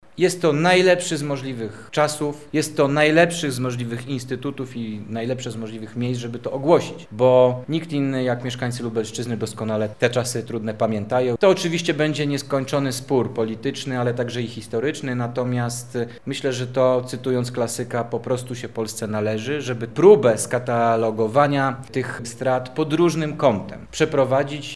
Ten temat poruszono podczas konferencji prasowej „Lublin. Wschodni wektor pamięci” w Muzeum Czechowicza.
– mówi minister Jakub Stefaniak, zastępca Szefa Kancelarii Prezesa Rady Ministrów.